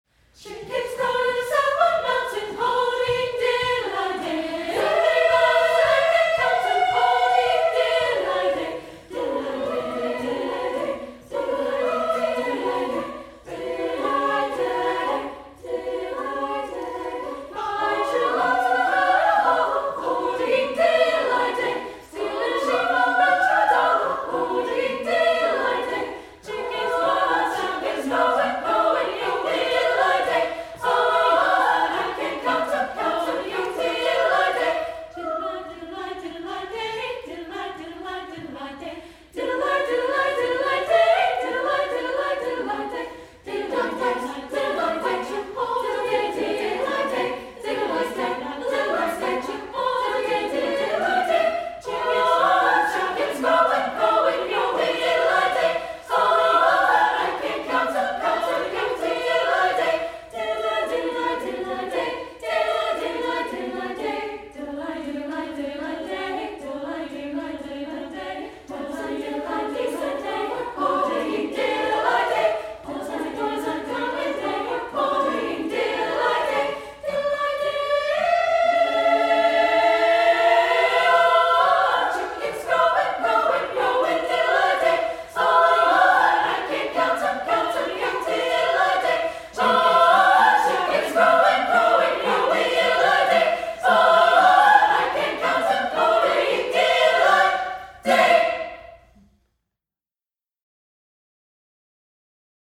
Composer: Appalachian Folk Song
Voicing: SSA a cappella